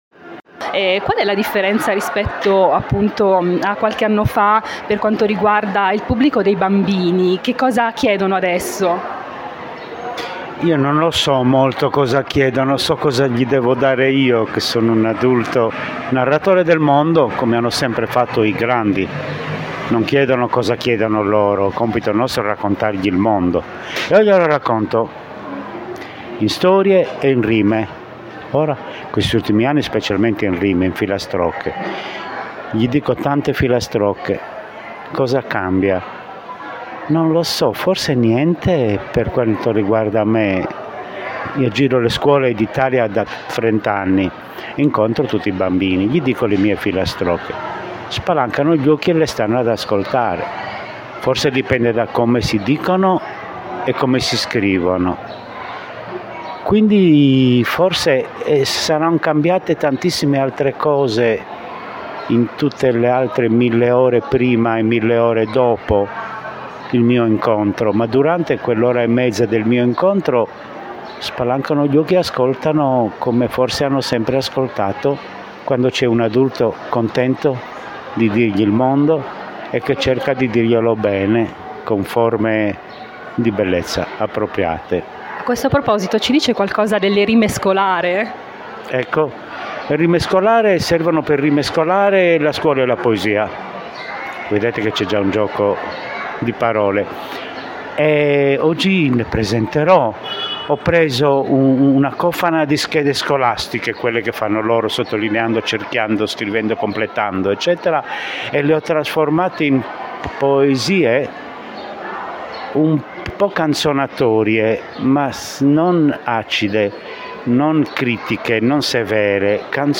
Le interviste di Taccuino Italiano
La Galleria Civica di Enna ha ospitato, dal 19 al 23 aprile, la XII edizione della Festa del Libro e della Lettura. In questa occasione abbiamo intervistato il grande Bruno Tognolini, poeta, scrittore e autore Rai (suoi i programmi iconici “Melevisione” e “L’Albero Azzurro”).